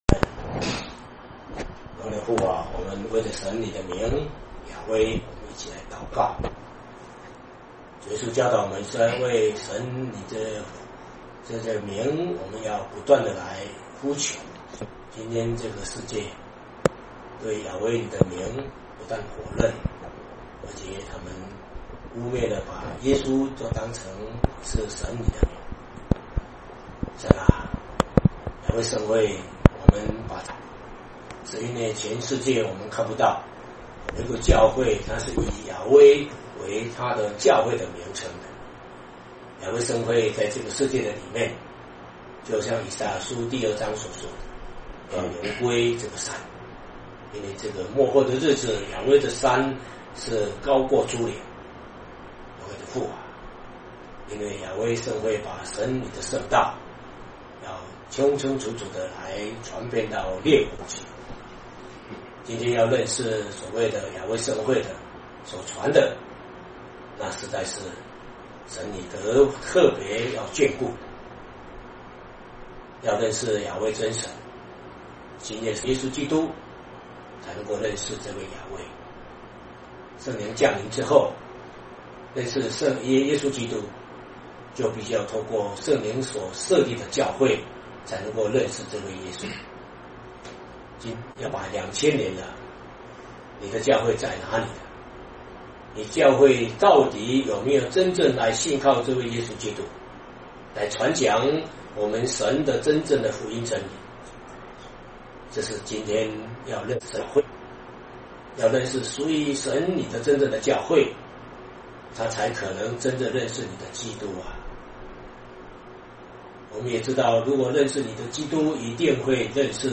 （馬可福音 11:1-11:26）講解 [雅威聖會 聖經真理研究院]
詩歌頌讚